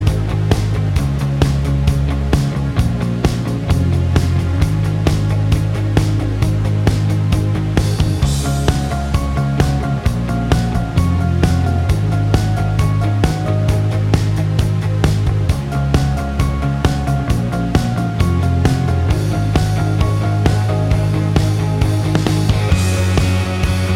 No Lead Guitar Pop